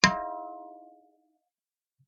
Thin bell ding 1
bell chime ding dong short sound effect free sound royalty free Sound Effects